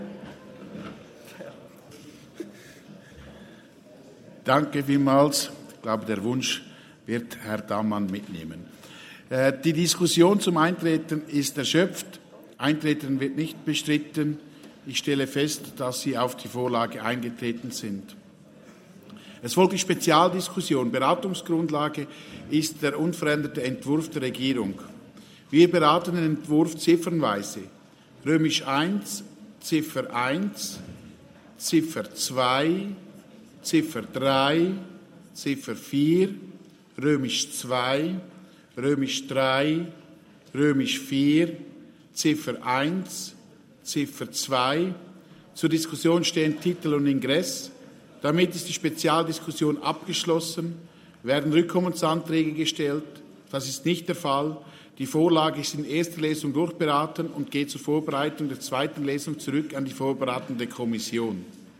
30.11.2022Wortmeldung
Session des Kantonsrates vom 28. bis 30. November 2022